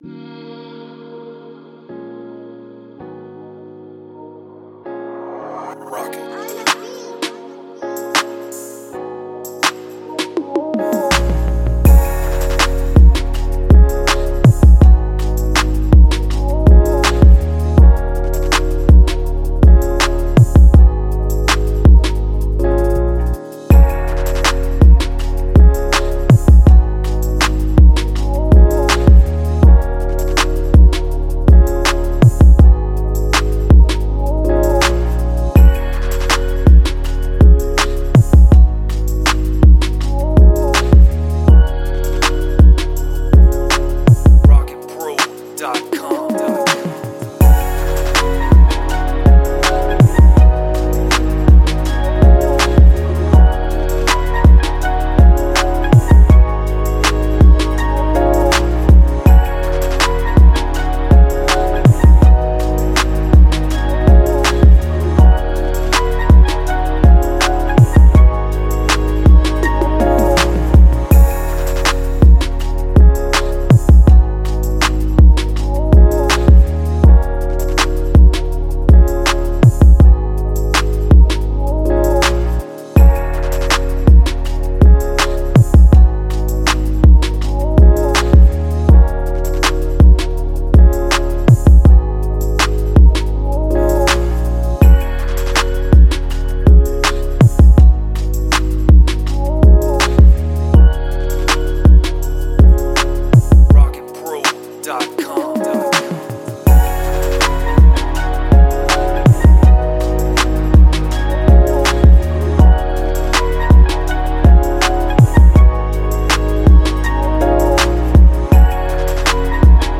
Club
R&B
West Coast